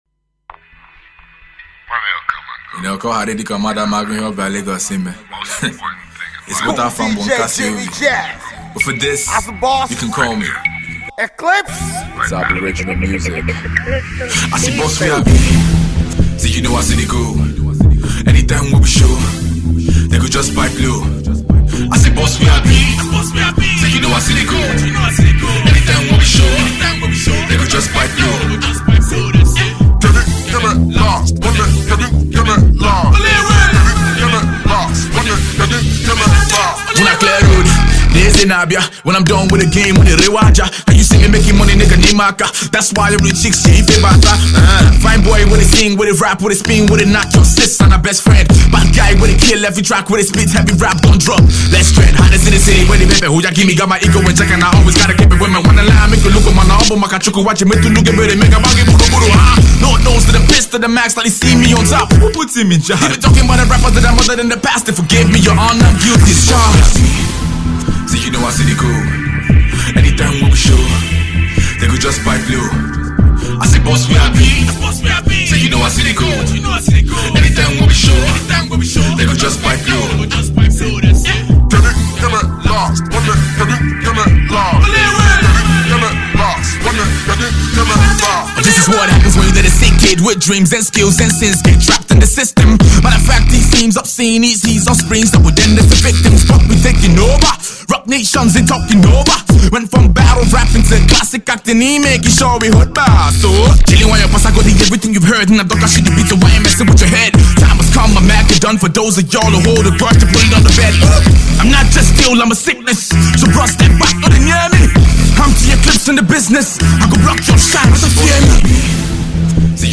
Hip-Hop
mixes elements of trap music with Afro Beats